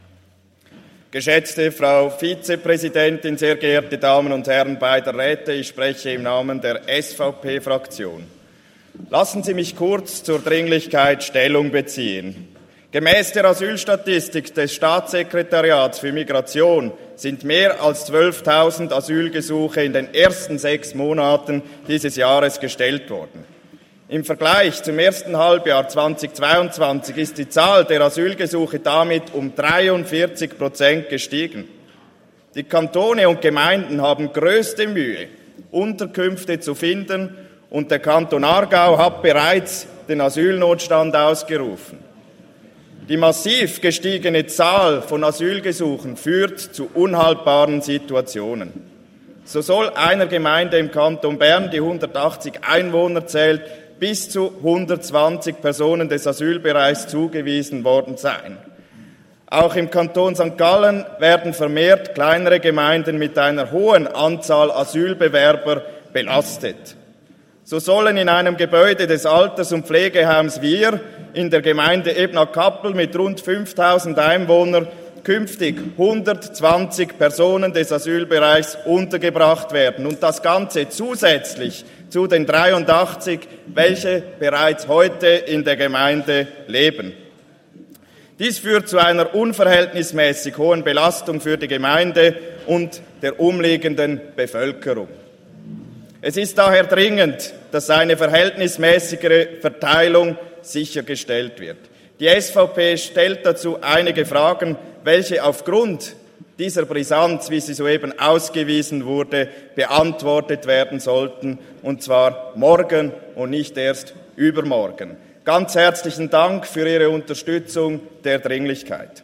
Session des Kantonsrates vom 18. bis 20. September 2023, Herbstsession